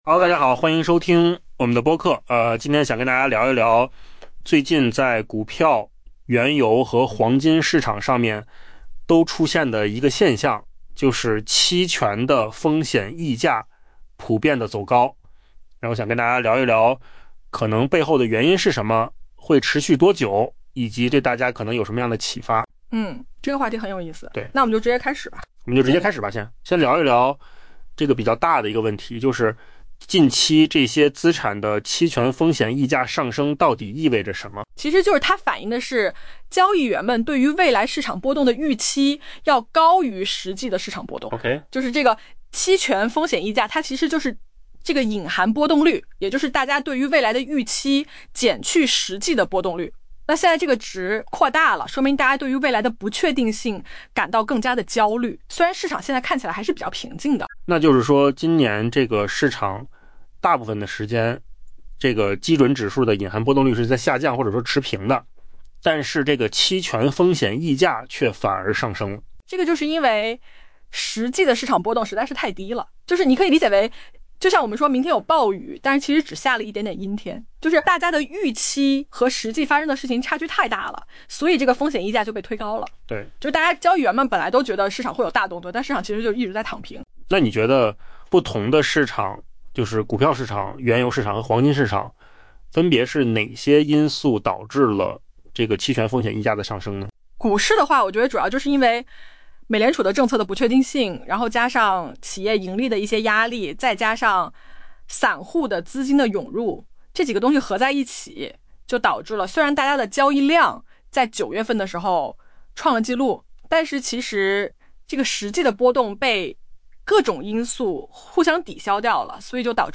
【文章来源：金十数据】AI播客：换个方
AI 播客：换个方式听新闻 下载 mp3 音频由扣子空间生成 尽管市场基准指数的隐含波动率今年大部分时间要么持平、要么下降，但 从股票到黄金等各类资产的期权风险溢价却在上升。